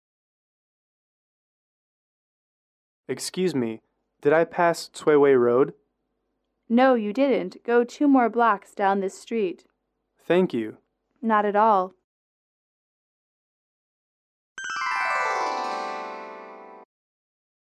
英语口语情景短对话52-1：去翠微路(MP3)